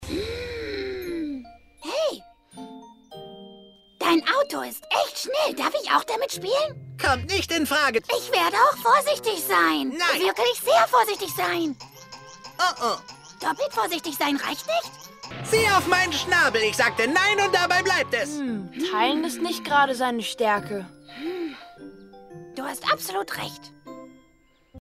komisch, bettelnd, nervend - Hello Kitty Serie